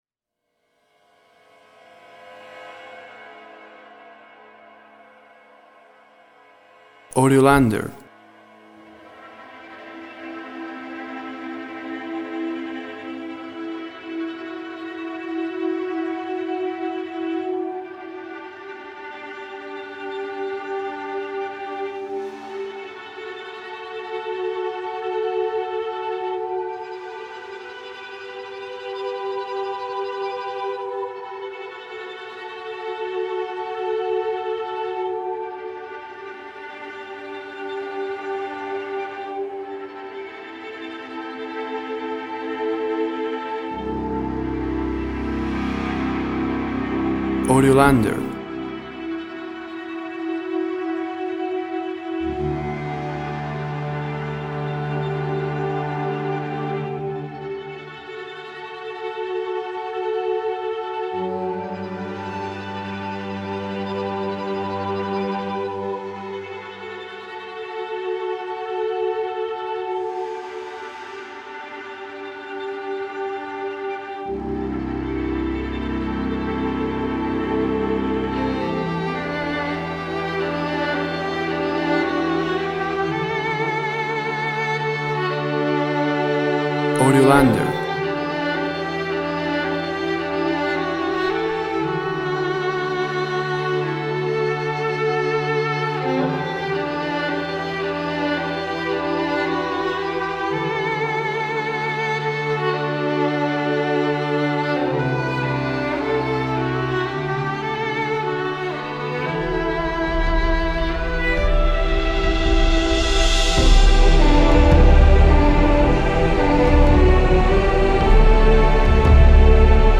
Sentimental orchestral track.
Tempo (BPM) 55